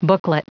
Prononciation du mot booklet en anglais (fichier audio)